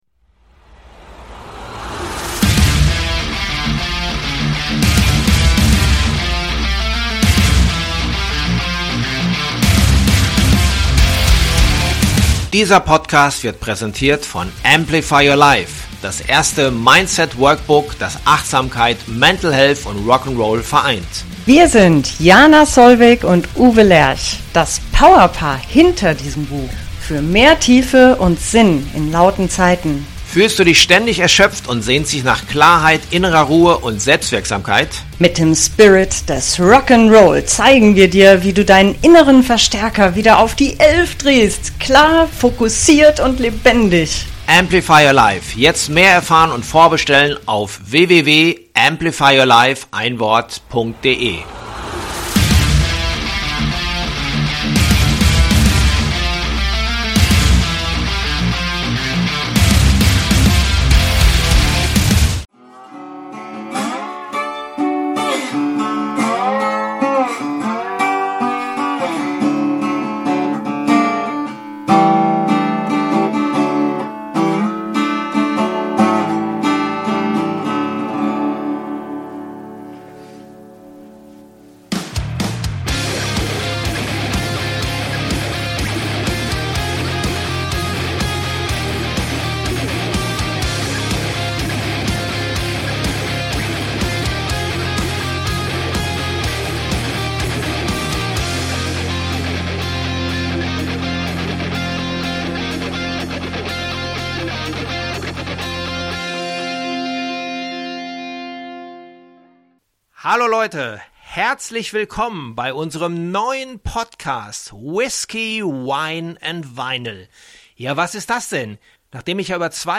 Aus Spaß wurde Ernst: "Whiskey, Wine & Vinyl" - der etwas andere Musik-Podcast! Ein handgemachtes Talk-Format, im klarem Kopf ausgedacht, nicht ganz nüchtern produziert als Event unter 3G Bedingungen - hier wird viel G-quatscht, G-sungen und G-soffen!